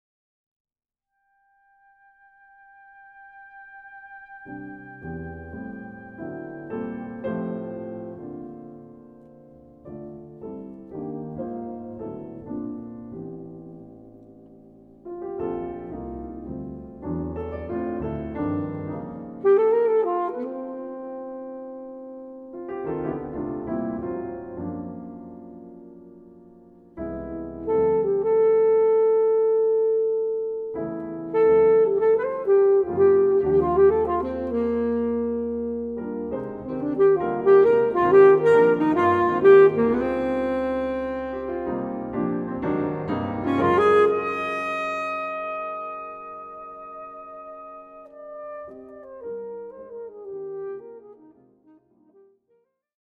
for alto saxophone and piano